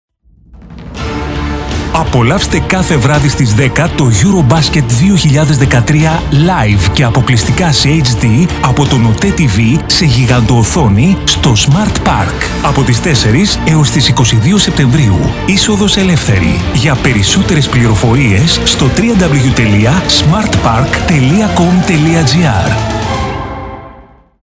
His deep textured voice is known for its versatility and trustworthy, one-on-one delivery style
Sprechprobe: Sonstiges (Muttersprache):